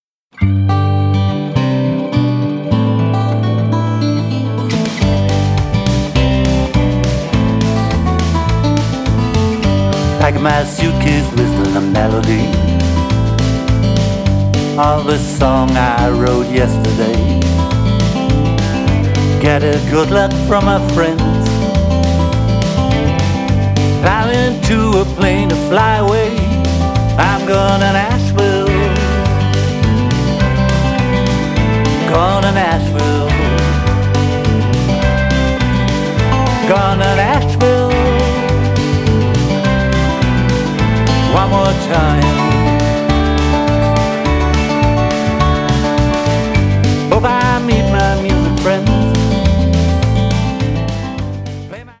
My journey towards Americana continues…
Vocals and harp
Drums, keyboards, string arrangement